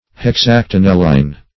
Search Result for " hexactinelline" : The Collaborative International Dictionary of English v.0.48: Hexactinelline \Hex*ac`ti*nel"line\, a. [From NL.